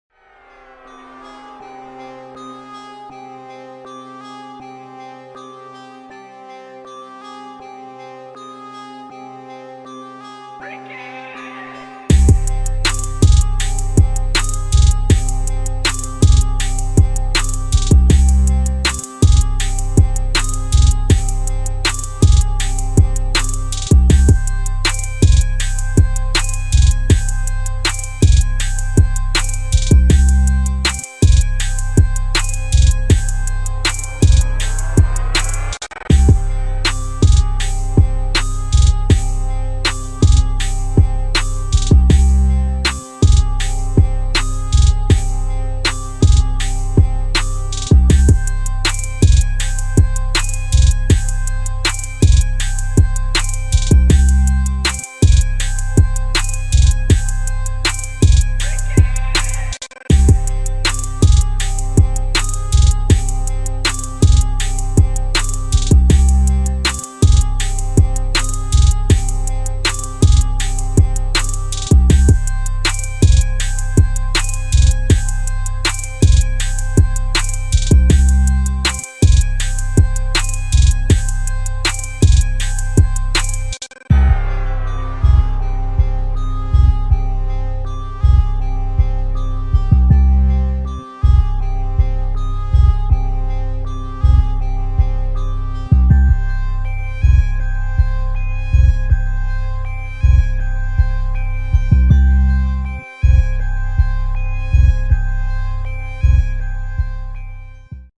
2024 in Official Instrumentals , Trap Instrumentals